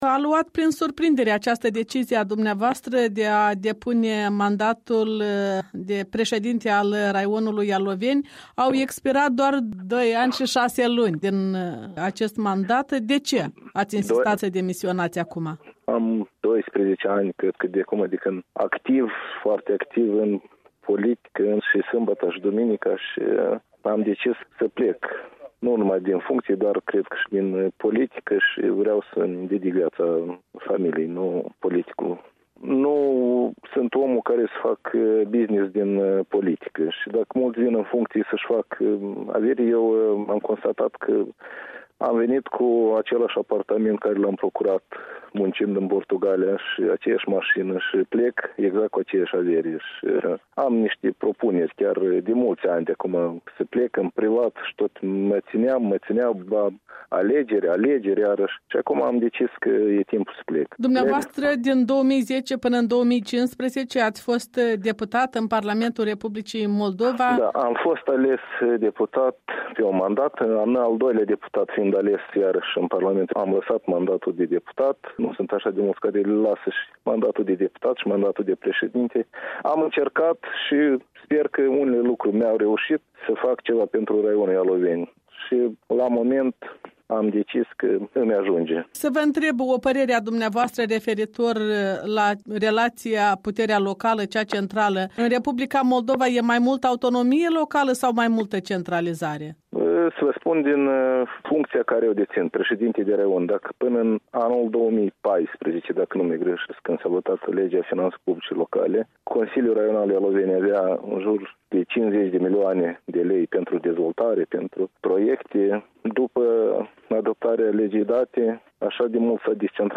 Un interviu cu președintele raionului Ialoveni